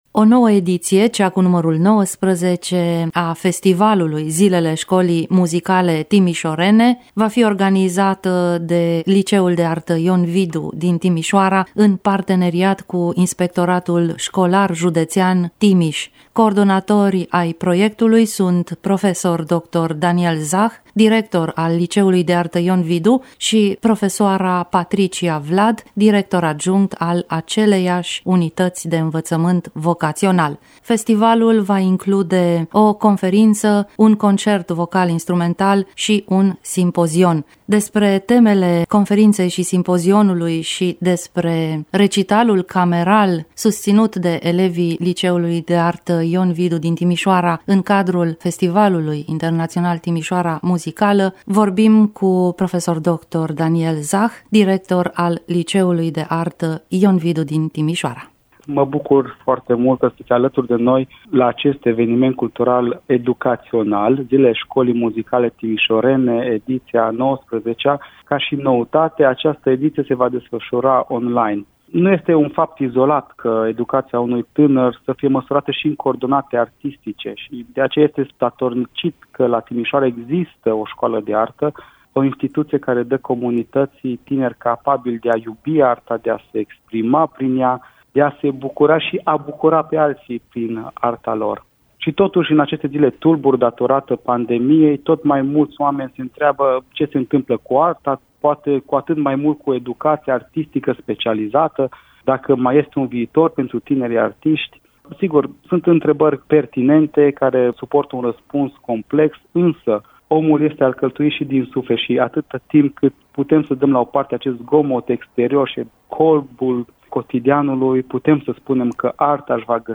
Detalii despre programul Festivalului “Zilele Şcolii Muzicale Timişorene”, în dialogul